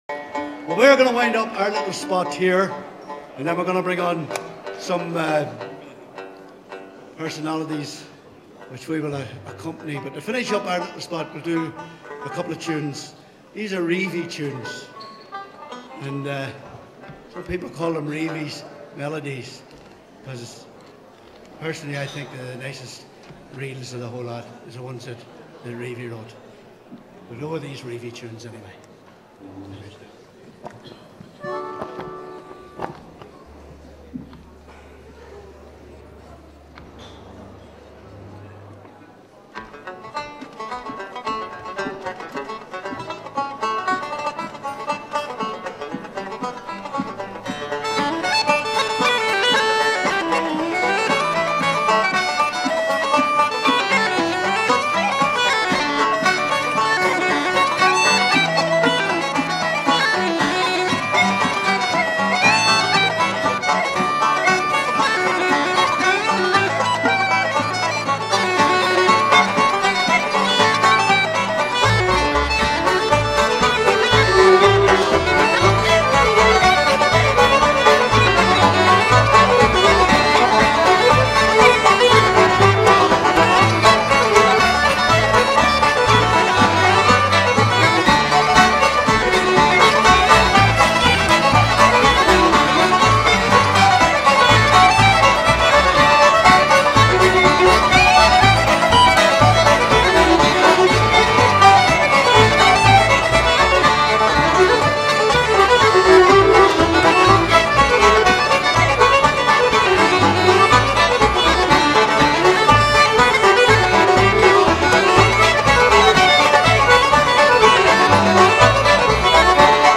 At last, the long-awaited 2006 REUNION CONCERT DVD soundtrack!!